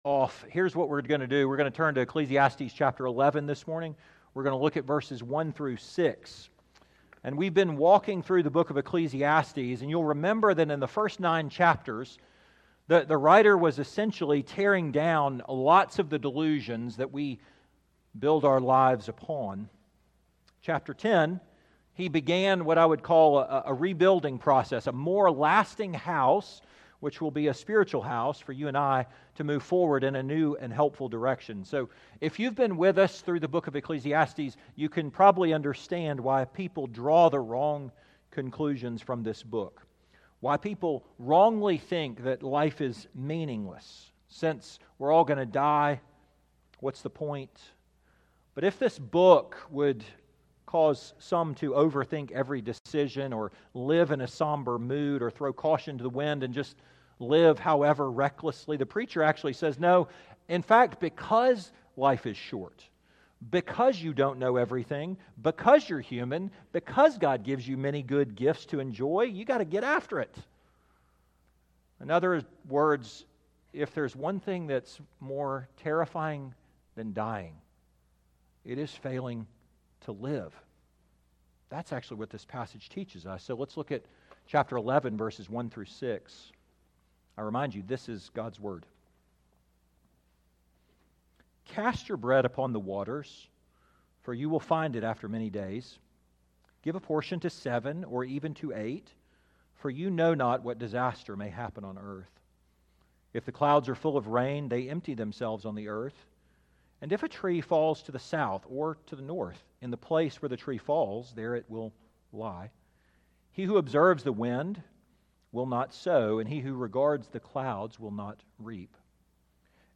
2025 Live Boldly Preacher